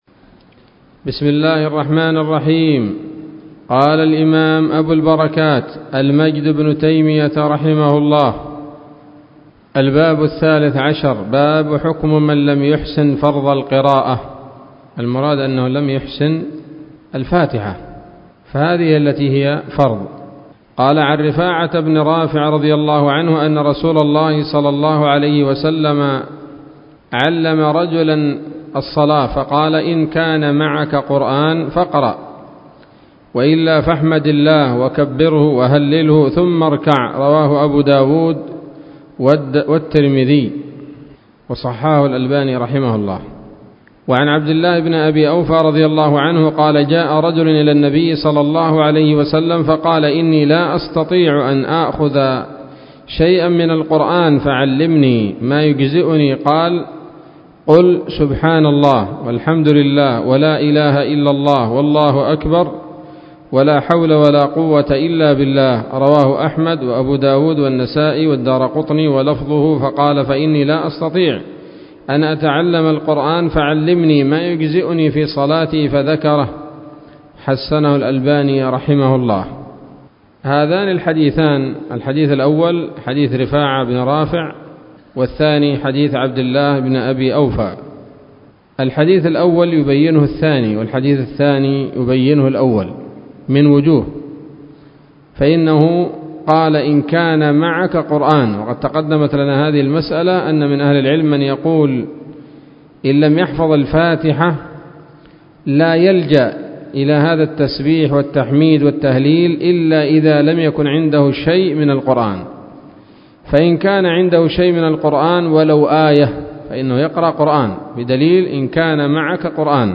الدرس الأربعون من أبواب صفة الصلاة من نيل الأوطار